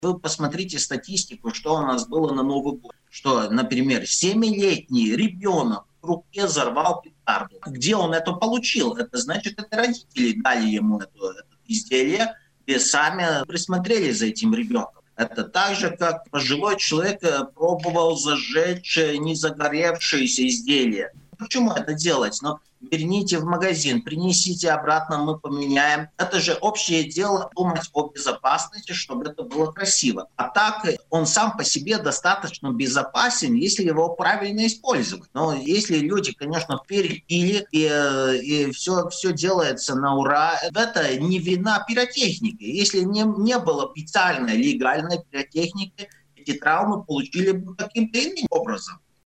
Травмы, связанные с использованием пиротехники, зачастую происходят из-за несоблюдения техники безопасности. Об этом рассказал в эфире радио Baltkom